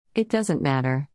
（イッダズント　マタァ）